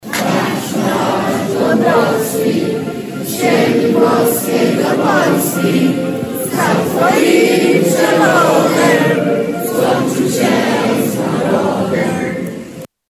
Równo w południe tłum bielszczan zgromadzonych pod ratuszem odśpiewał „Mazurek Dąbrowskiego”.
Hymn śpiewali przedstawiciele miejskich władz, harcerze i mieszkańcy.